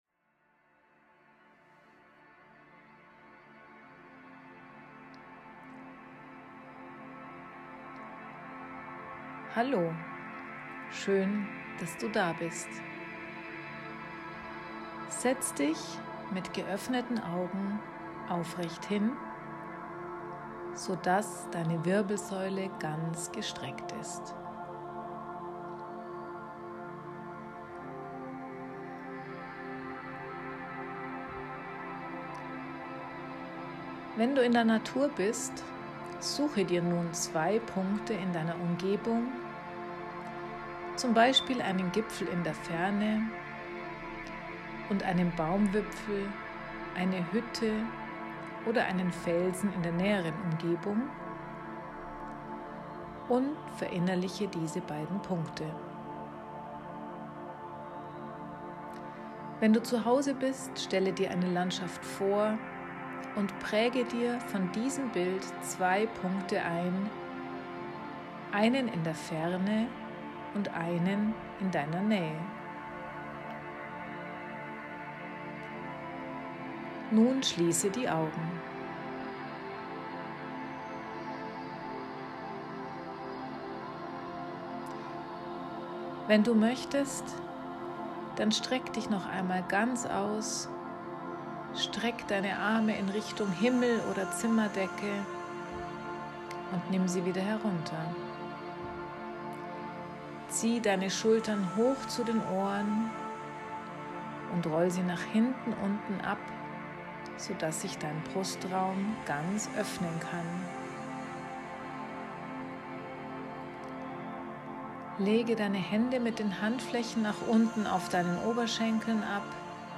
fokusmeditation_final.mp3